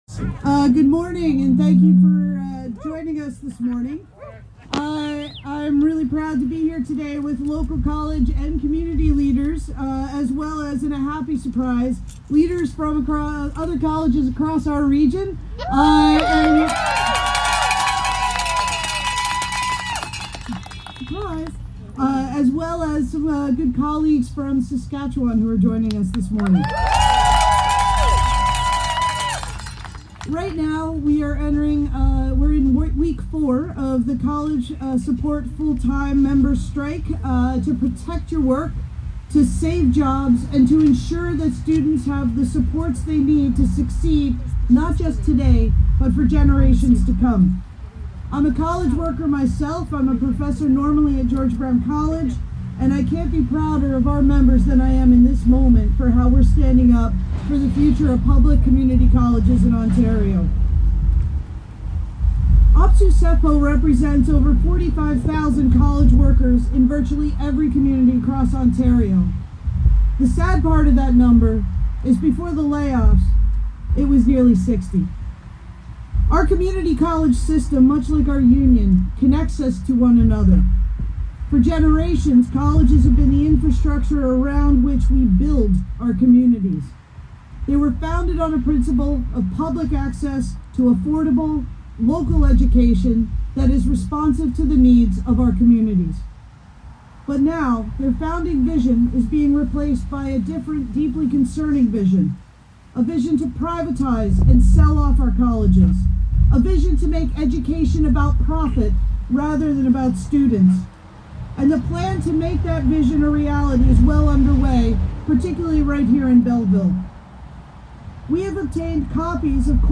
Hundreds of OPSEU members from the Quinte area’s post secondary institution, St Lawrence College in Kingston, and Algonquin College in Ottawa were also on hand.